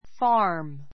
fɑ́ː r m ふァ ー ム